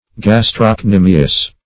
Gastrocnemius \Gas`troc*ne"mi*us\, n. [NL., from Gr. ? the calf